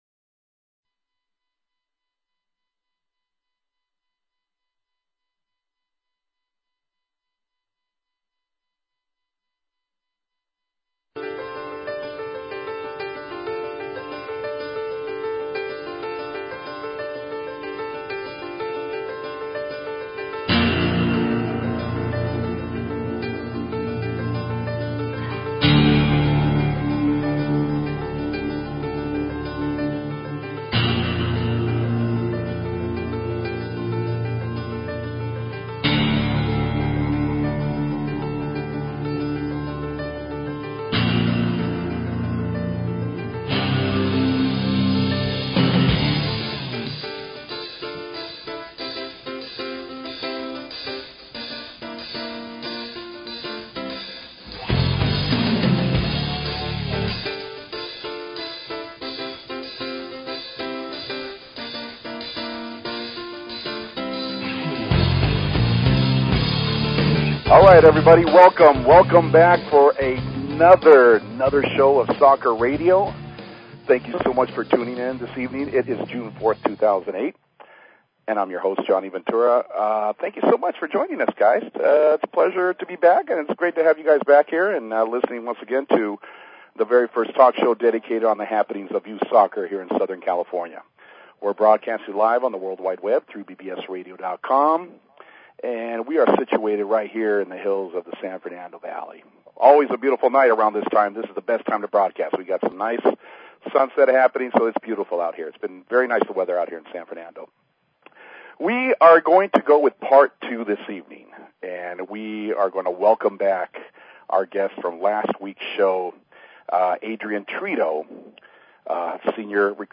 Talk Show Episode, Audio Podcast, Soccer_Radio and Courtesy of BBS Radio on , show guests , about , categorized as
The show's one hour format will consist of covering Coast Soccer League, CYSA State Cup, recreational leagues, high school, tournaments with live remotes, along with season coverage of the MLS.
Guest appearances by players, coaches and live phone calls are also part of the shows format.